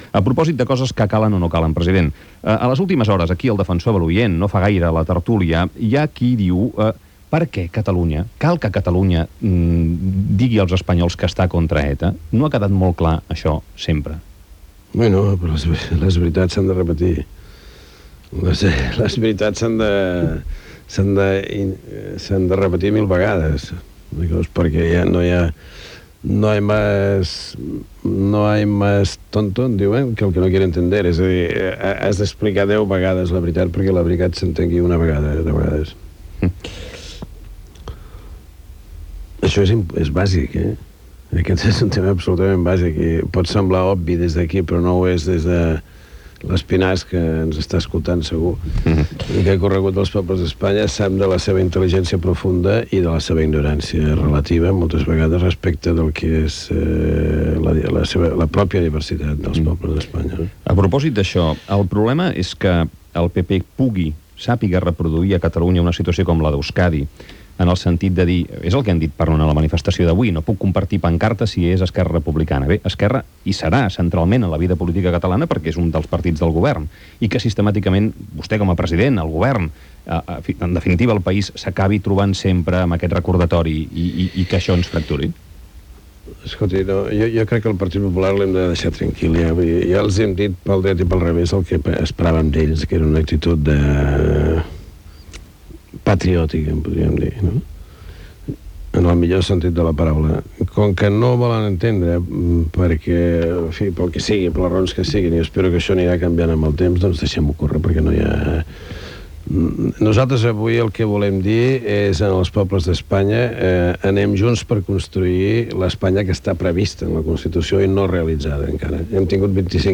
Entrevista al president de la Generalitat Paqual Maragall sobre la relació Catalunya Espanya, paraules del president de la comunitat Murciana i l'abastament d'aigua
Info-entreteniment